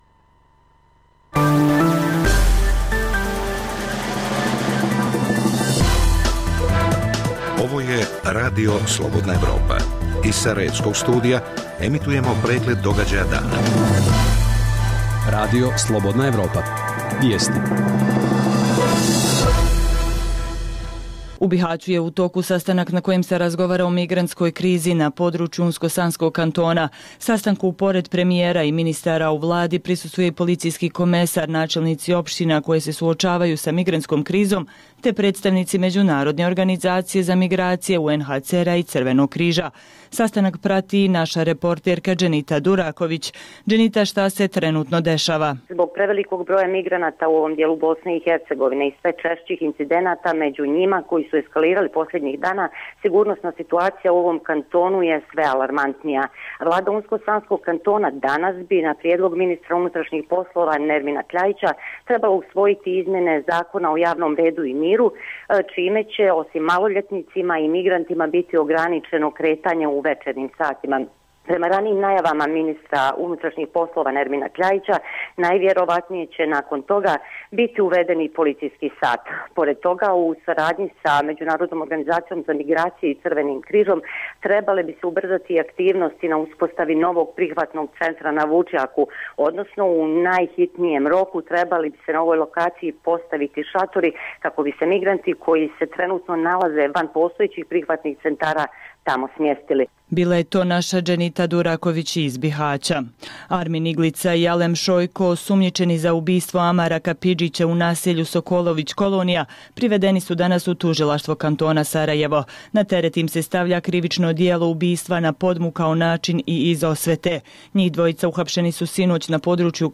vijesti, analize, reportaže